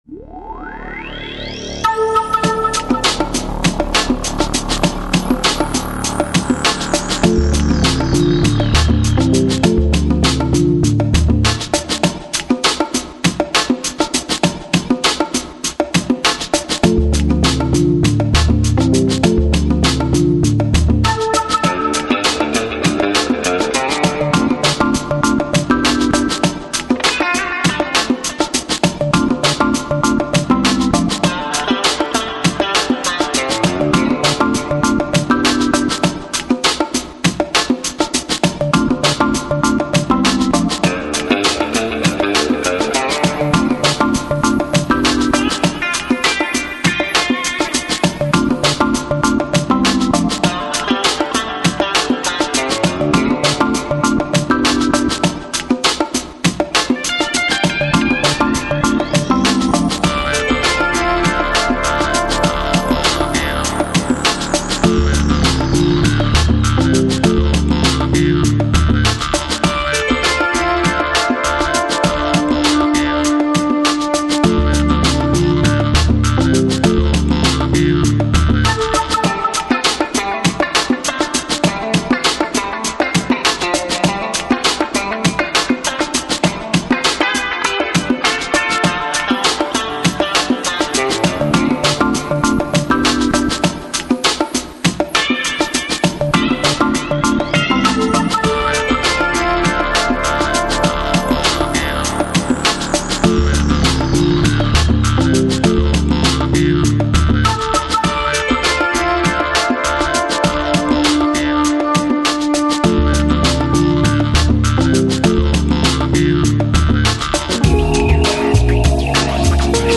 FLAC Жанр: Lounge, Downtempo Издание